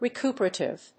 re・cu・per・a・tive /rɪk(j)úːpərèɪṭɪv‐p(ə)rə‐/
発音記号
• / rɪk(j)úːpərèɪṭɪv(米国英語)